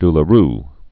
(dlə-r)